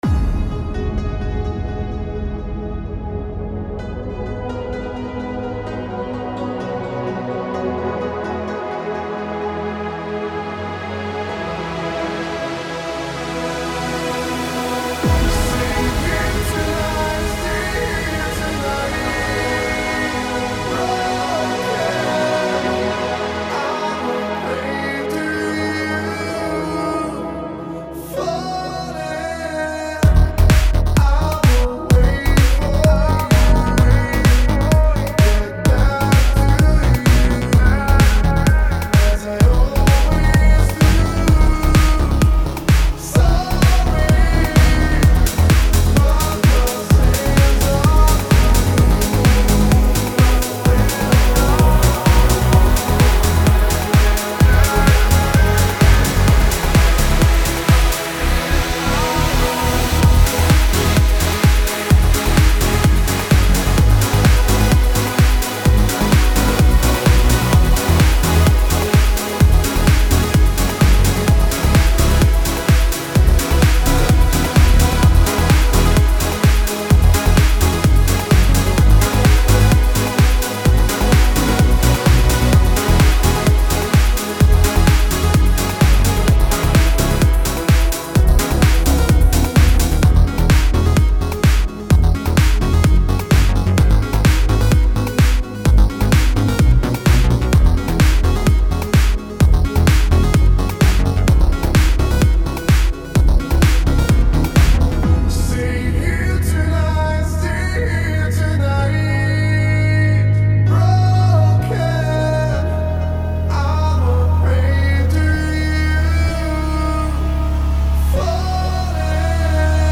Still Here Tonight [Pop/EDM]
Решил что нибудь накидать жарким летним вечером) Музыка и аранжировка моя, акапелла хз чья, с просторов Ютуба, сведение ничье) Может когда нибудь сведу)